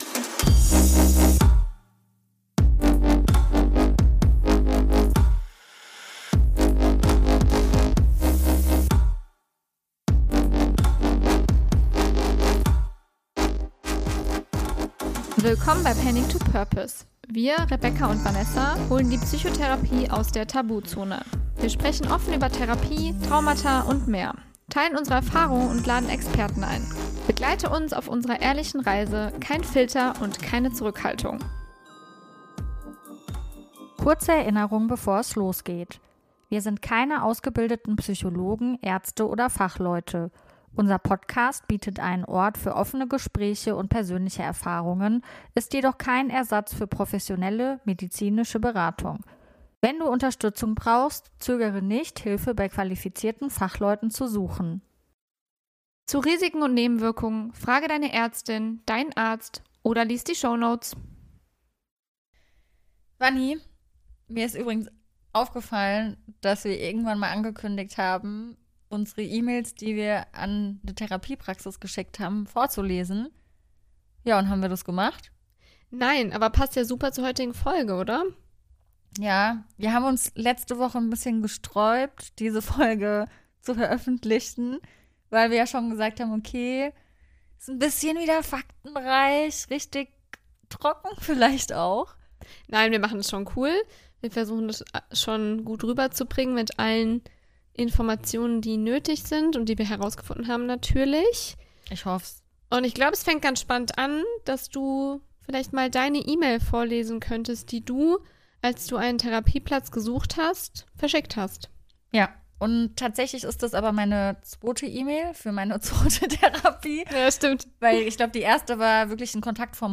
Und vor allem: wenn ich das Problem bin, mit welcher Therapieart könnte ich das gut angehen? Natürlich darf unsere persönliche Note nicht fehlen: humorvoll, ehrlich und mit ein paar Anekdoten aus unserem eigenen Leben. Es gibt jede Menge Lacher, ehrliche Meinungen und vielleicht sogar den einen oder anderen Aha-Moment!